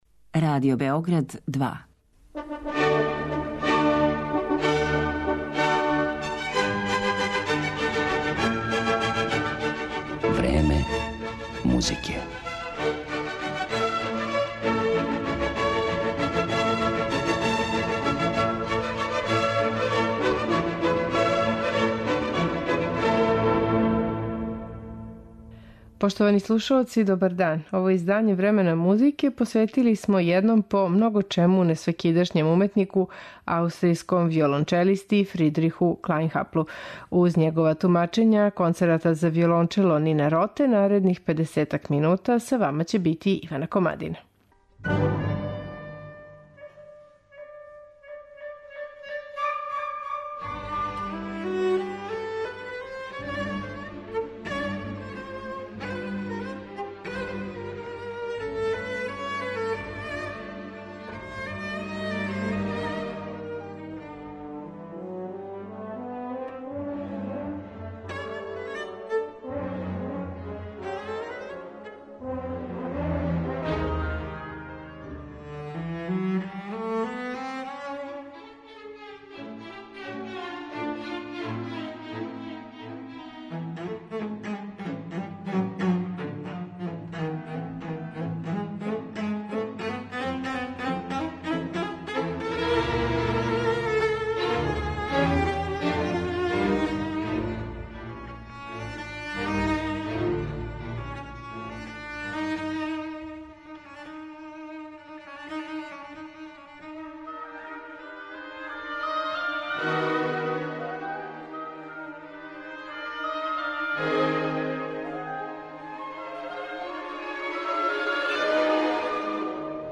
Страствени интерпретативни стил и техничка виртуозност
концерата за виолончело